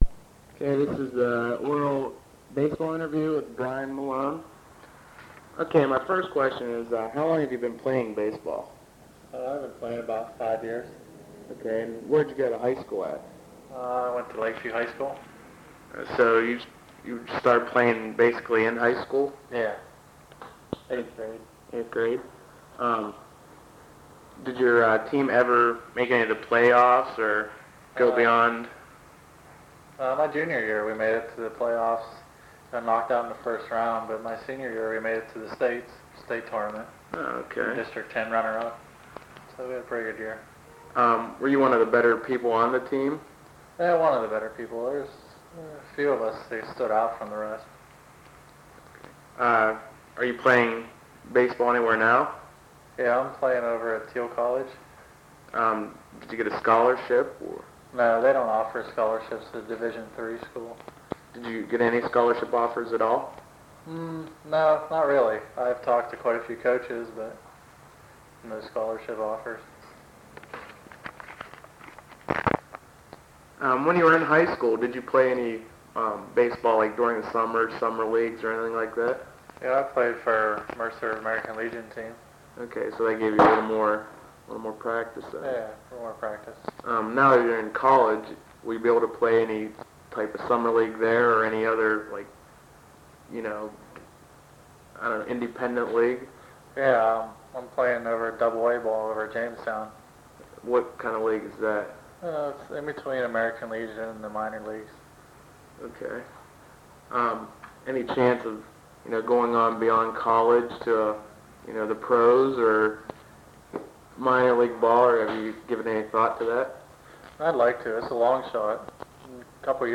Oral History Project